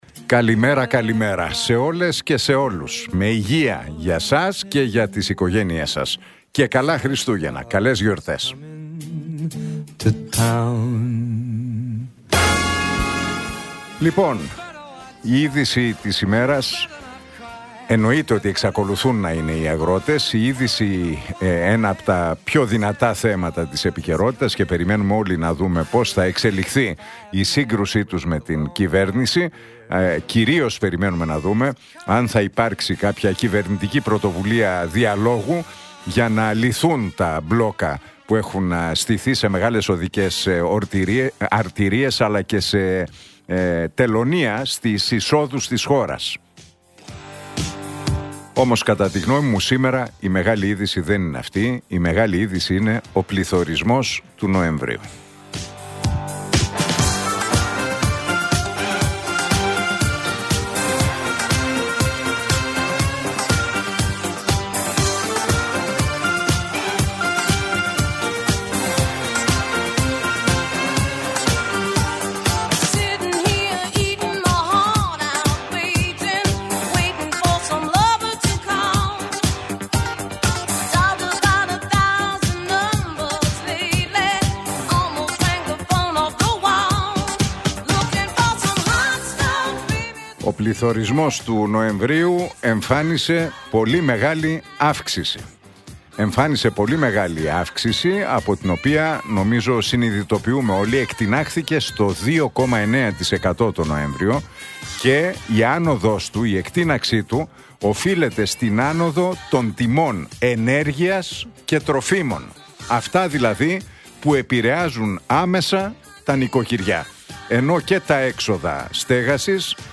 Ακούστε το σχόλιο του Νίκου Χατζηνικολάου στον ραδιοφωνικό σταθμό Realfm 97,8, την Τετάρτη 3 Δεκεμβρίου 2025.